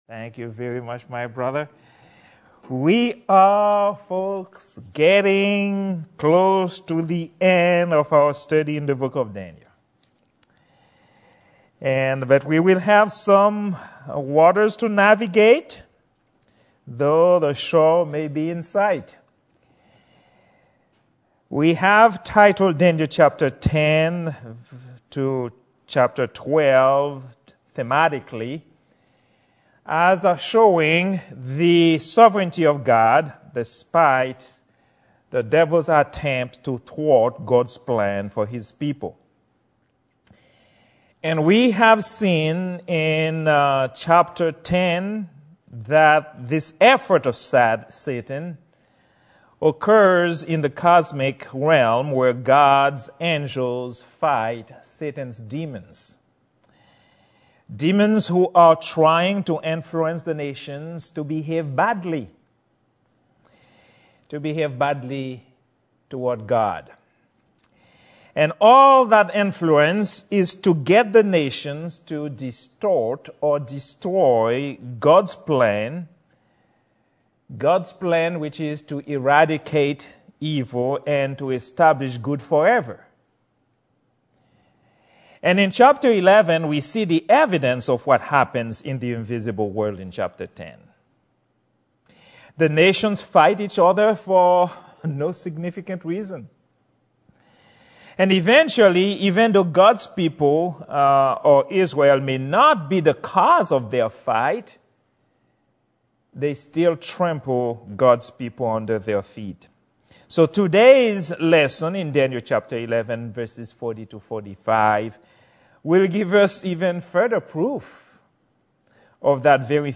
Prayer-Meeting-10-13-21_01.mp3